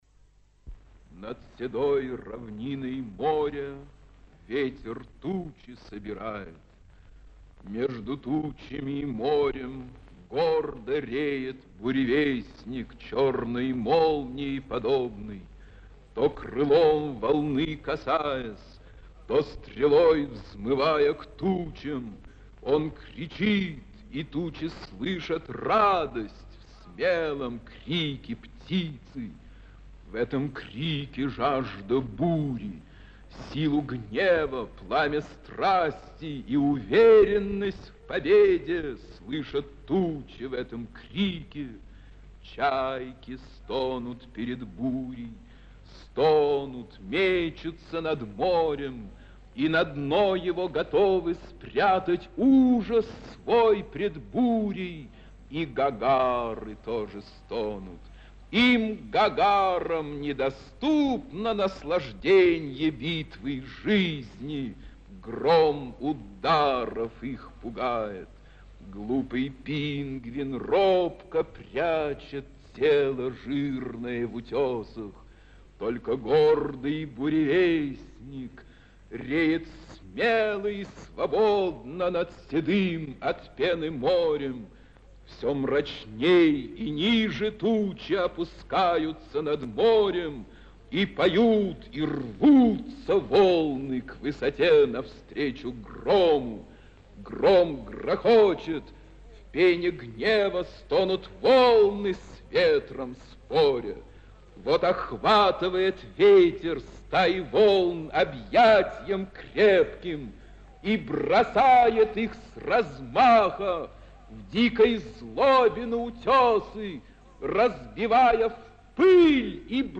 Исполнитель В. Яхонтов.
Д. Возвышенный тон, высокая патетика, сильная эмоциональная приподнятость, яркая выразительность, напряжение, серьезность...